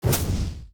Torch Attack Strike 2.ogg